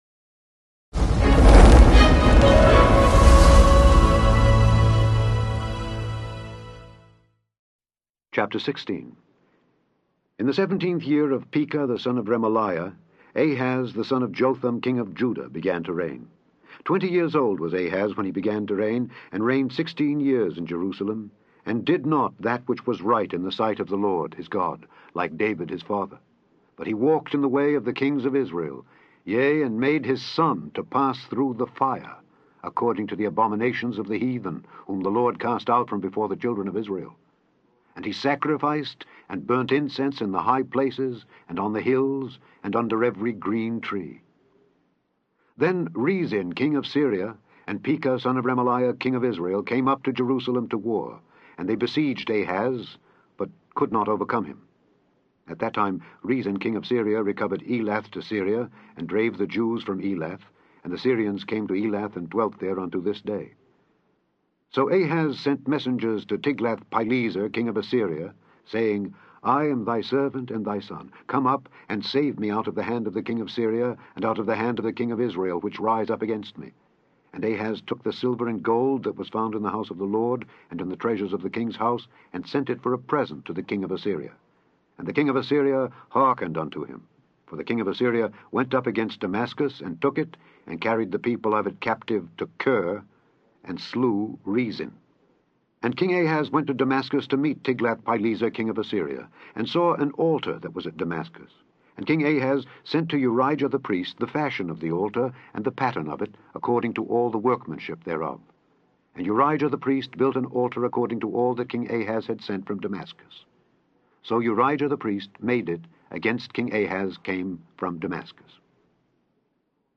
Click on the podcast to hear Alexander Scourby read II Kings 16-20.